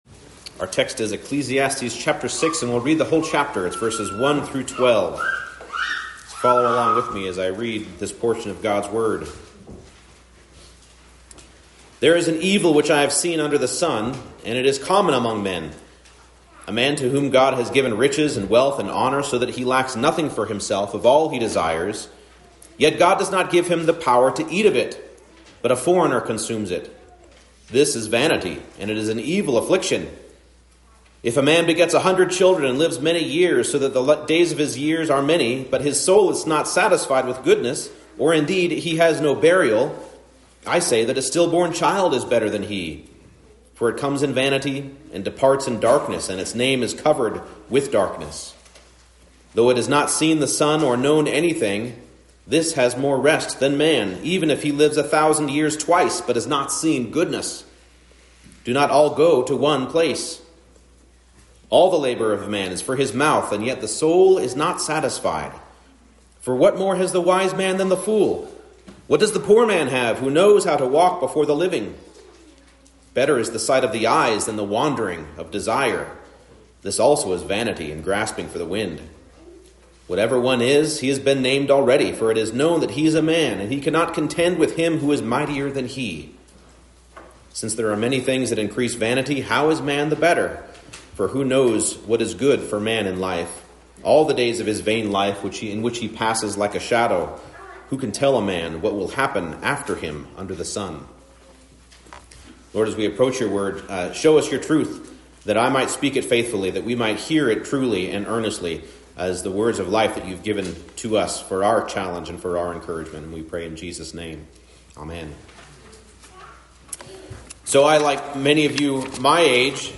Ecclesiastes 6:1-12 Service Type: Morning Service Enjoying God’s pleasures means enjoying them in Him.